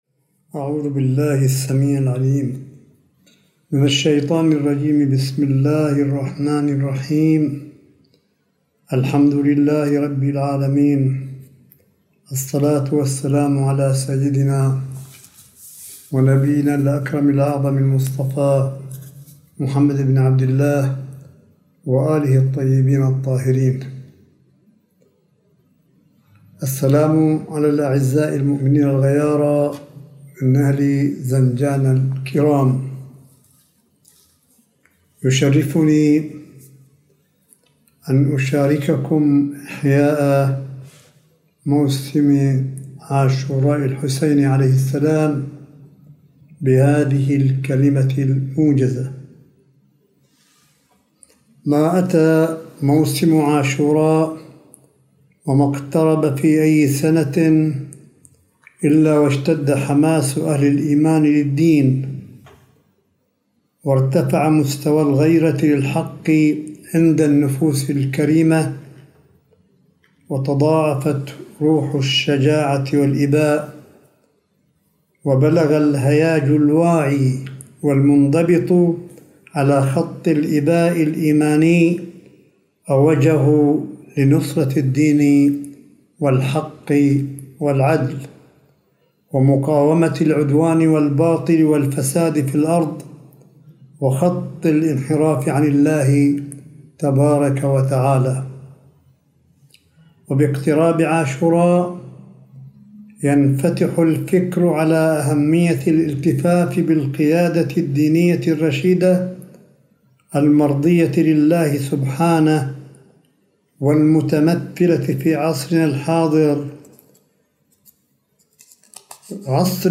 ملف صوتي لكلمة سماحة آية الله الشيخ عيسى أحمد قاسم أمام الآلاف من أهالي مدينة زنجان شمال غرب الجمهورية الإسلامية في مراسم عاشوراء 1442هـ